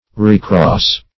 Recross \Re*cross"\ (r?*kr?s";115), v. t. To cross a second time.